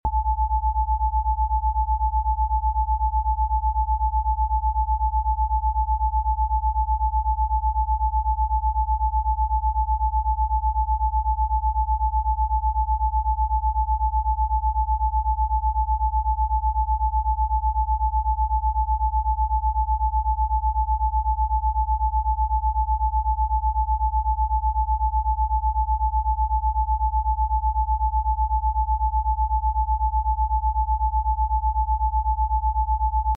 El hongo cándida albicans puede causar desequilibrios en tu cuerpo, afectando tu sistema inmunológico, piel y bienestar general. Esta frecuencia Rife 464 Hz ha sido asociada con la desactivación de patógenos como la cándida.